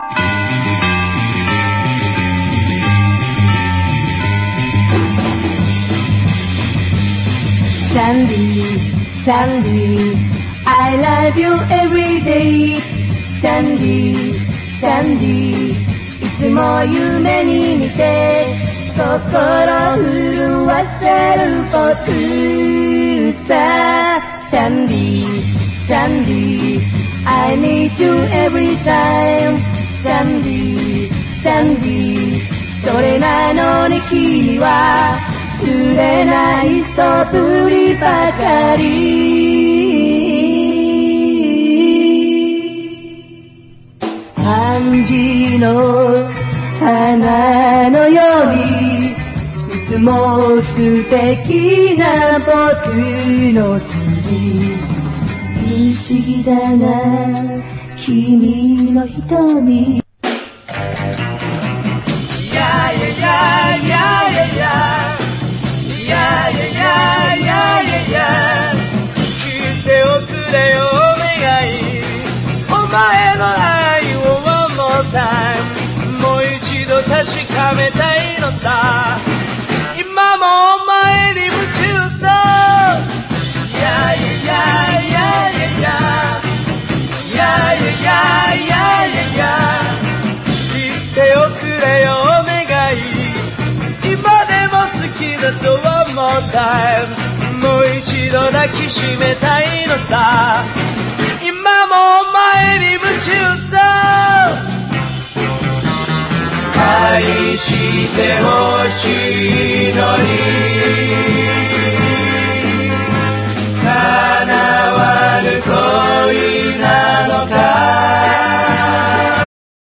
GS / GARAGE ROCK / 60'S BEAT
サイケデリックにうねるオルガン＆グルーヴィなビートが◎